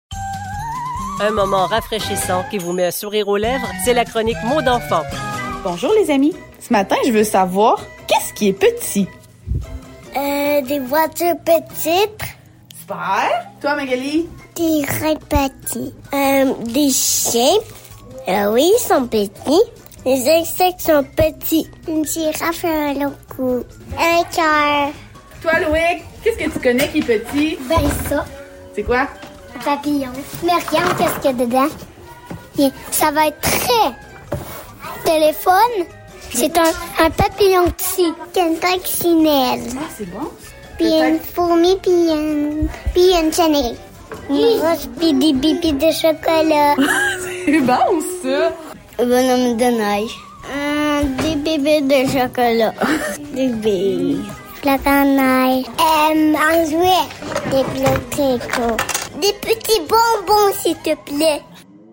Les enfants nous informent aujourd'hui sur ce qu'ils trouvent petit.